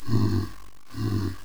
elewalk2.wav